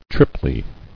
[trip·ly]